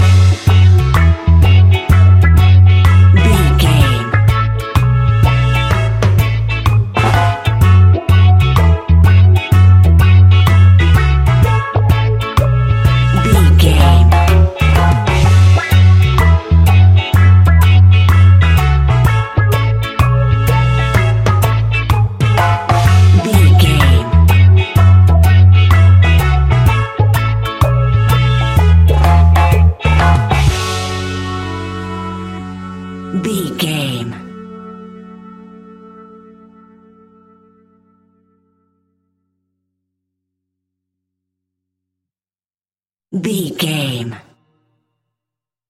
Classic reggae music with that skank bounce reggae feeling.
Uplifting
Aeolian/Minor
F#
dub
laid back
chilled
off beat
drums
skank guitar
hammond organ
percussion
horns